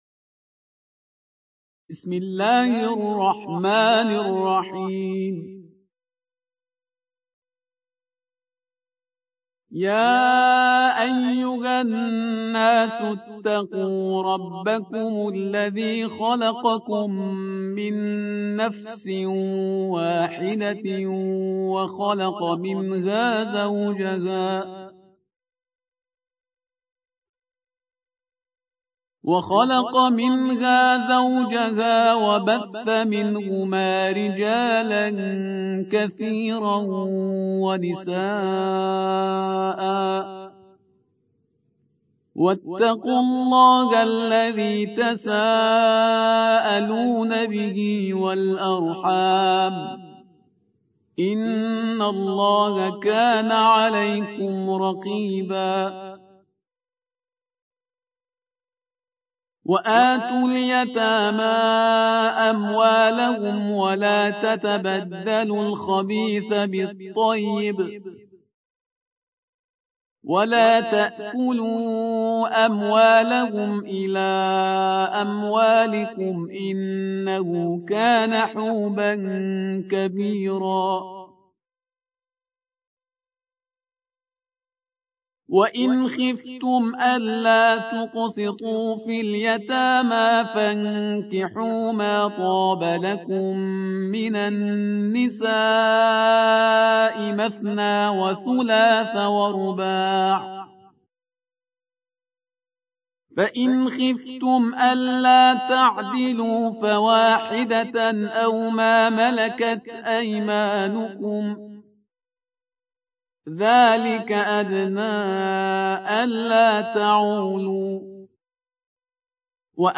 4. سورة النساء / القارئ